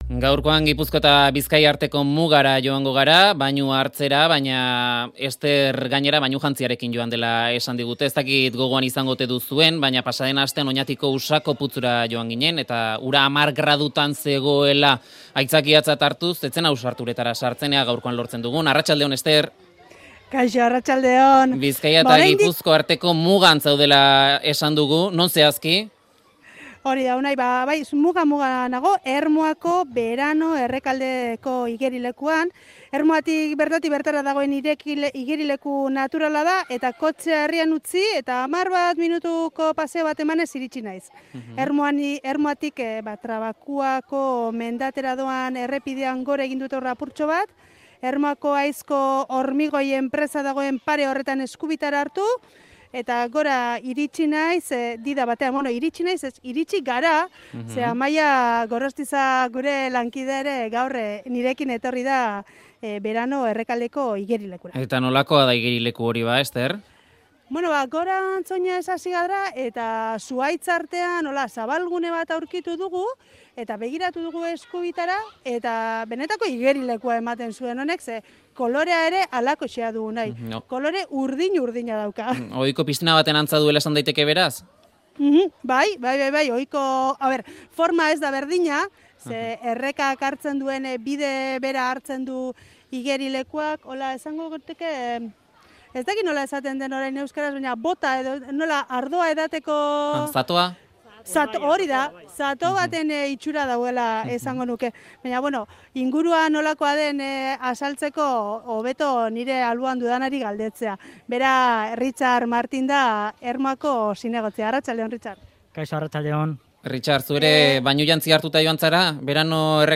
Richard Martin zinegotziak kontatu dizkigu sekretuak. Sorosle zerbitzua dute eta bainu eta aisialdirako lekua ere eskaintzen du herrian bertatik bertara dagoen Berano Errekaldek.